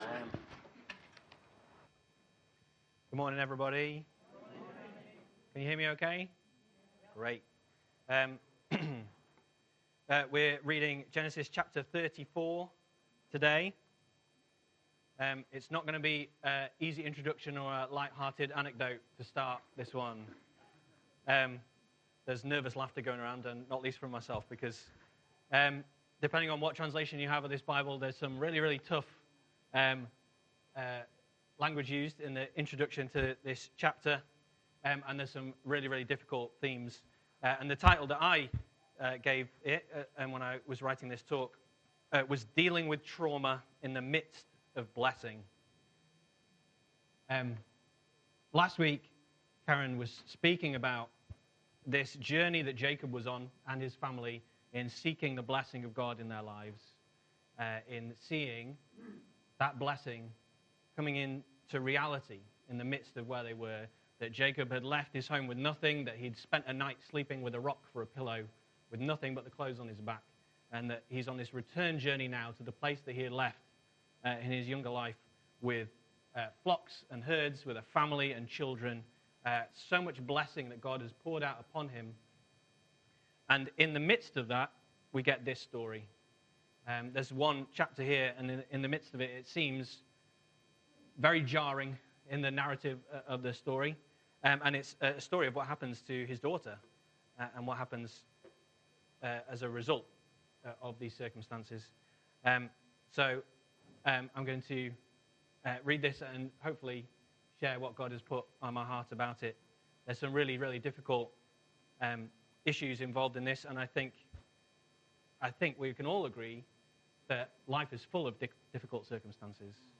Sunday Gathering – Genesis – Repairing the Damage
Genesis Sunday Gathering – Genesis – Repairing the Damage November 17, 2024 Sermon Summary: Blessing and Reconciliation Scripture References: Genesis 33 Key Themes: God's Sovereignty and Blessing: God's promises to Abraham and his descendants. The importance of God's blessing in one's life.…